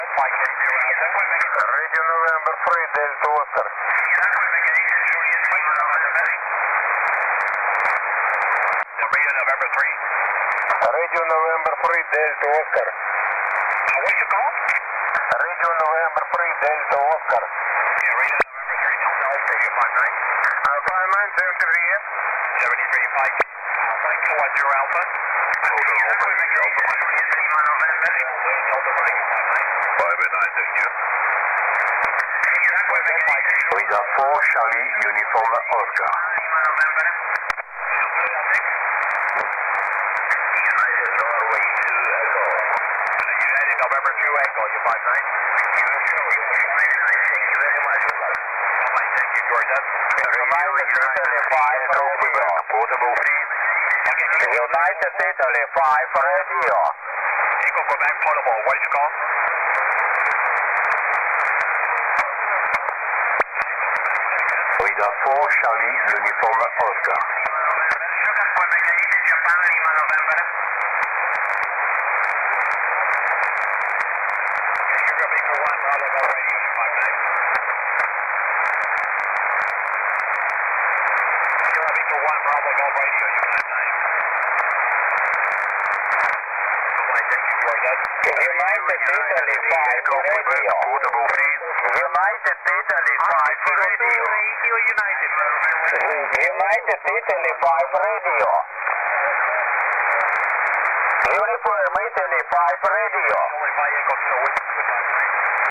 5K0A 20m SSB 27/11/2014 San Andres and Providencia rx in EU